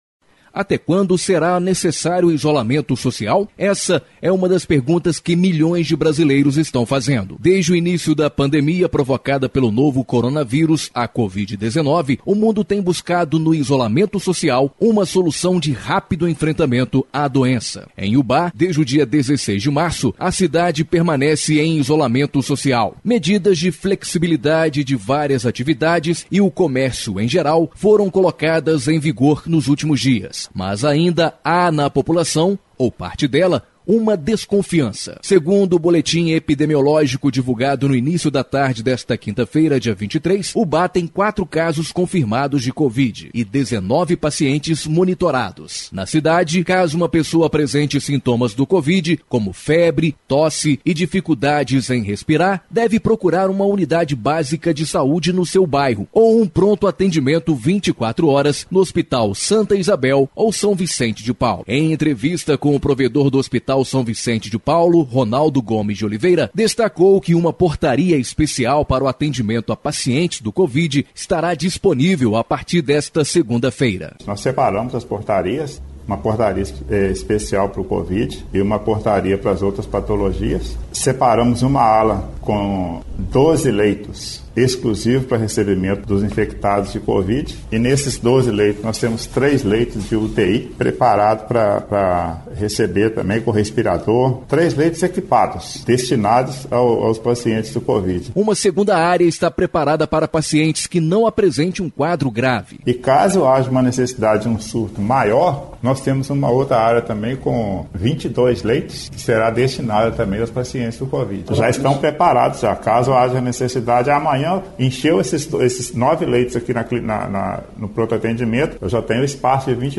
Reportagem exibida na Rádio Educadora AM/FM